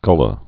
(gŭlə)